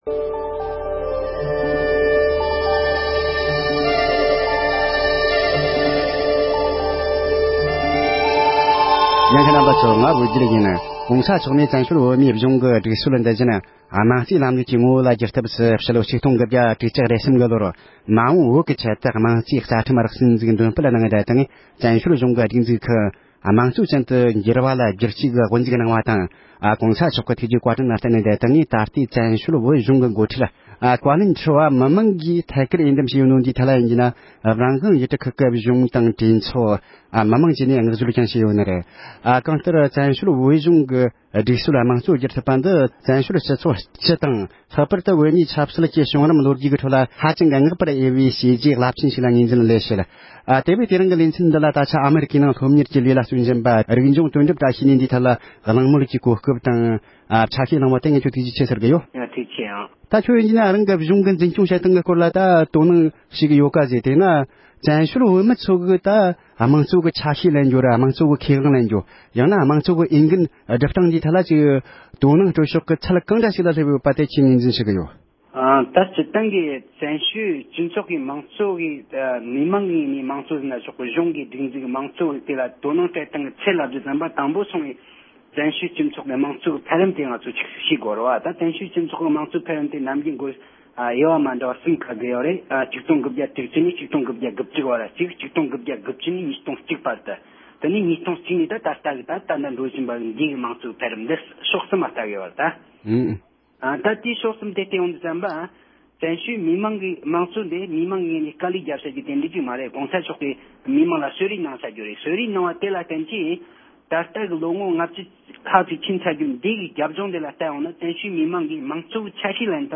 བོད་ཀྱི་མང་གཙོ་དང་བཀའ་ཁྲིའི་འོས་འདེམས་ཀྱི་གནས་སྟངས་ལས་མ་འོངས་པའི་བོད་ཀྱི་ལས་དབང་ལ་ཇི་ལྟར་བལྟ་རྒྱུ་ཡིན་ཞེས་པའི་ཐད་གླེང་མོལ།